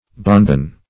Bonbon \Bon"bon`\, n. [F. bonbon, fr. bon bon very good, a